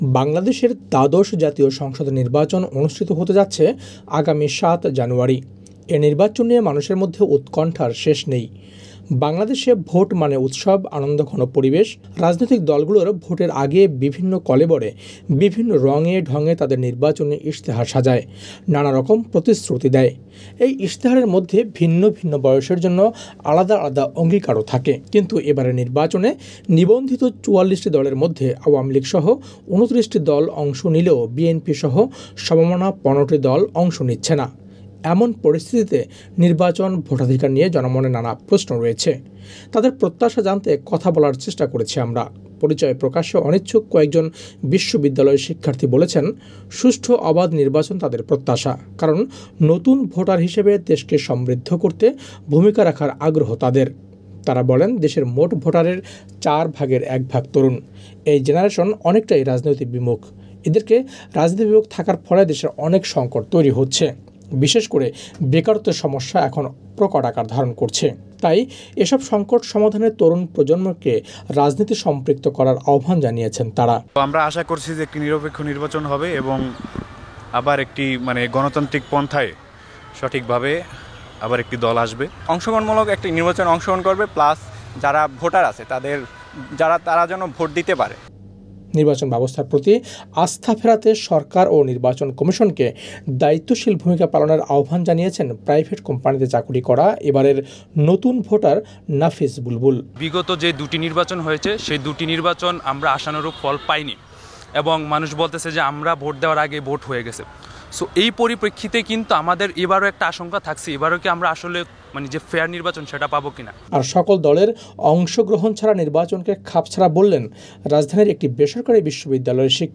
তাদের প্রত্যাশা জানতে কথা বলা চেষ্টা করেছি আমরা। পরিচয় প্রকাশে অনিচ্ছুক কয়েকজন বিশ্ববিদ্যালয় শিক্ষার্থী বলছেন, সুষ্ঠু অবাধ নির্বাচন তাদের প্রত্যাশা।